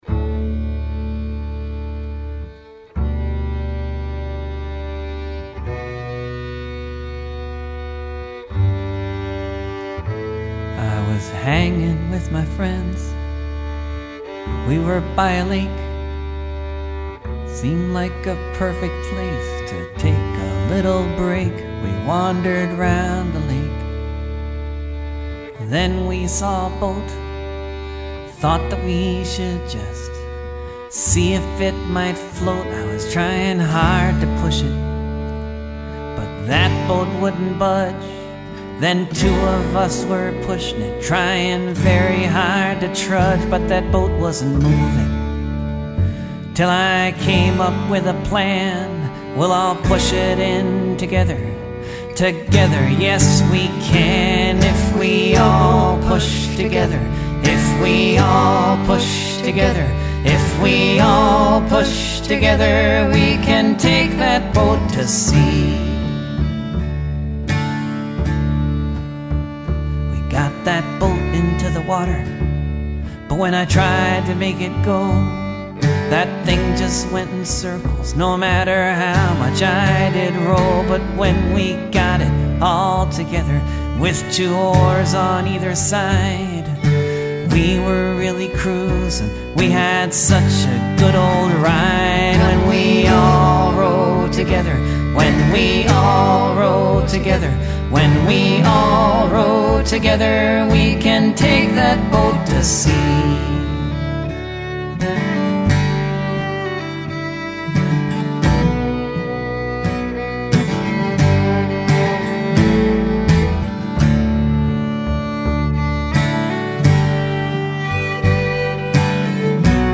children’s music